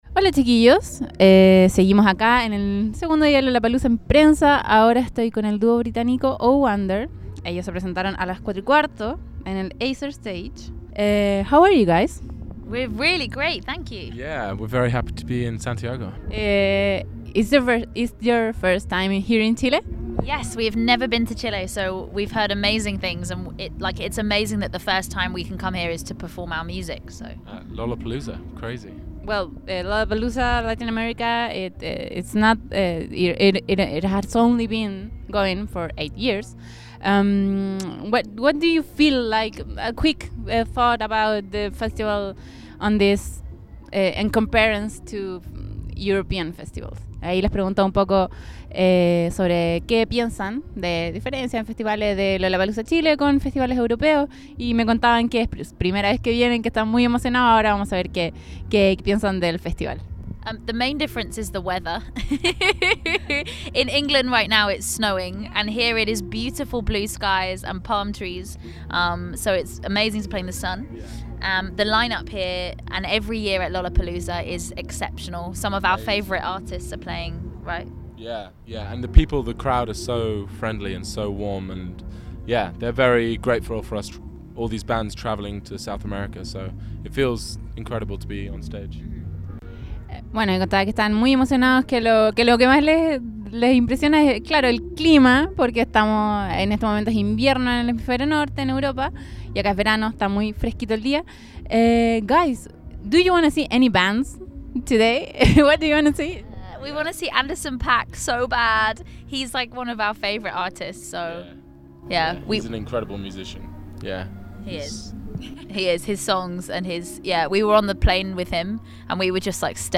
Revive la entrevista en exclusiva que tuvimos con Oh Wonder — Rock&Pop
Lollapalooza Chile: Revive la entrevista en exclusiva que tuvimos con Oh Wonder